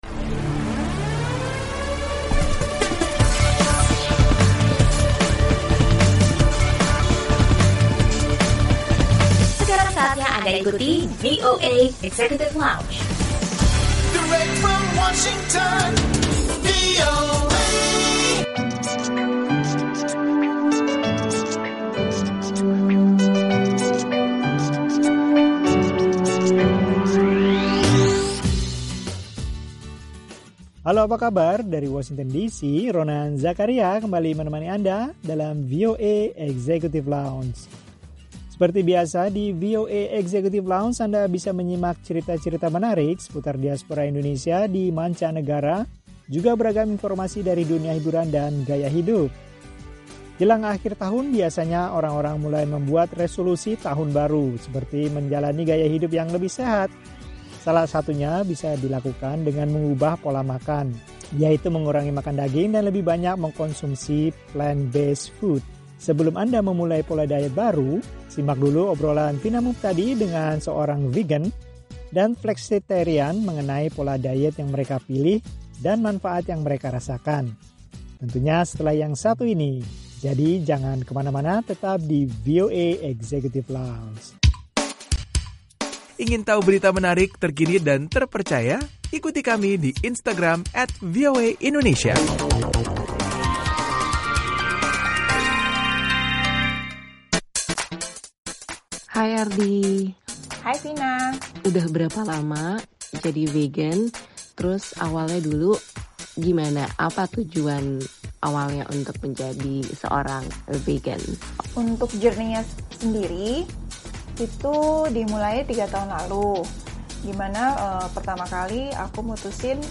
Obrolan tentang manfaat pola diet Vegan dan Flexitarian bersama seorang Vegan dan Flexitarian.